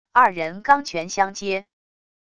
二人钢拳相接wav音频